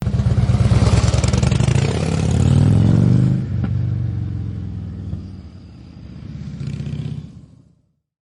Motorcycle Pull Away
SFX
yt_xwO7WLi5Y4A_motorcycle_pull_away.mp3